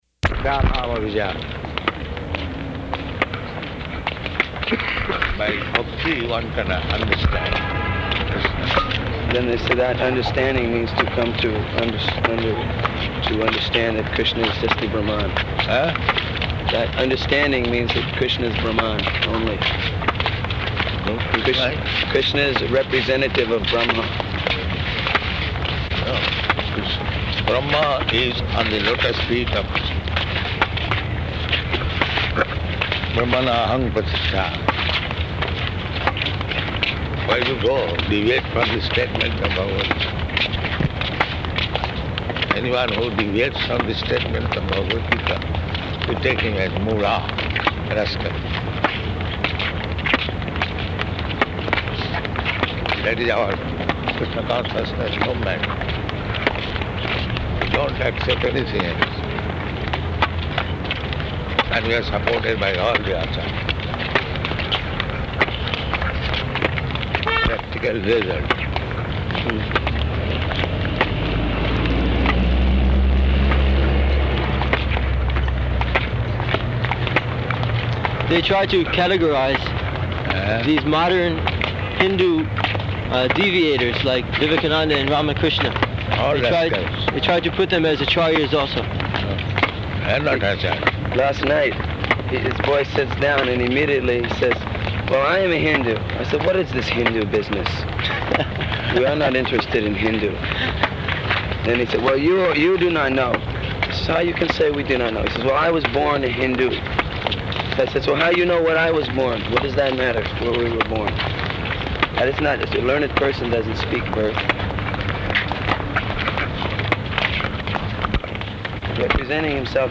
Morning Walk --:-- --:-- Type: Walk Dated: April 26th 1976 Location: Melbourne Audio file: 760426MW.MEL.mp3 Prabhupāda: ...mām abhijānāti.